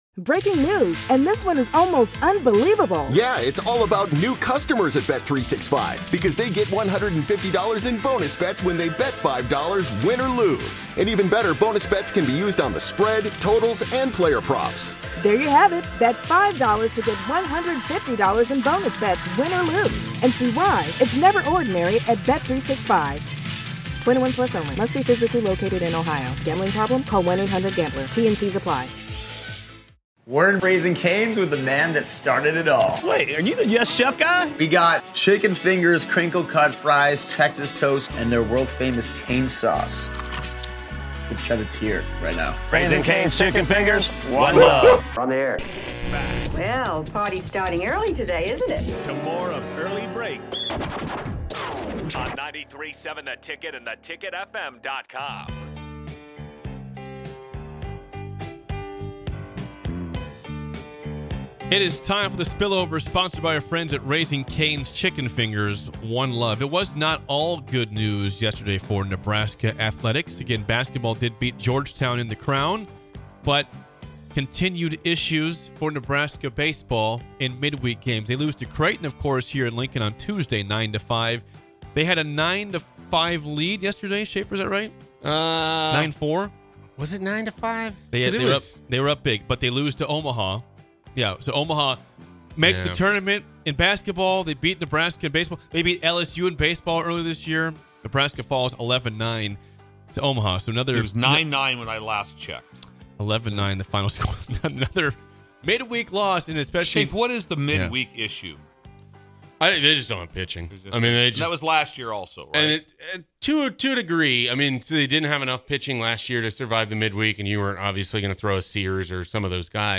Join these three goofballs from 6-8am every weekday morning for the most upbeat and energetic morning show you'll ever experience. Grab a cup of coffee, turn up the volume, and imagine you're right alongside them in studio!!